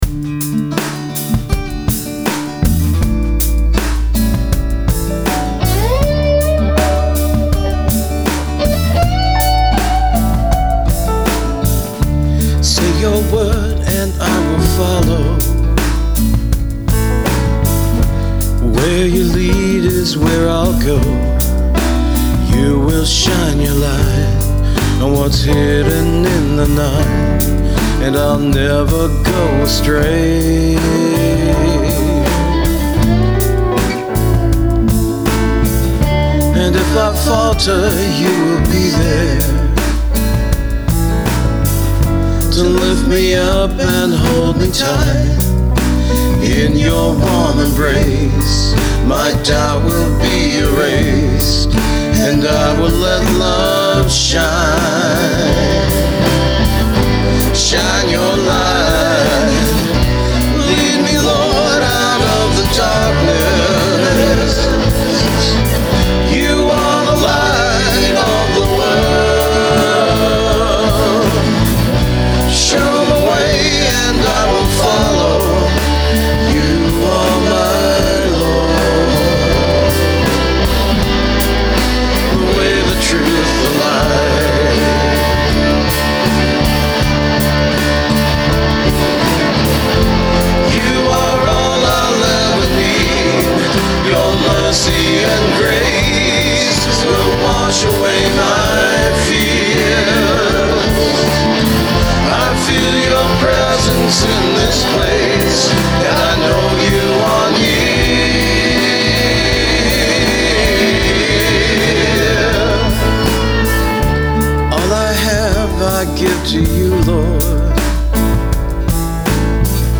It produces tons of mid-range that cuts right through a mix.
So I finally got it put together, and after I recorded the acoustic guitar and piano parts, realized that it needed a driven electric guitar sound; specifically, my Strat through a cranked Marshall.
When I want a smooth drive with lots of mid-range, the middle pickup is it!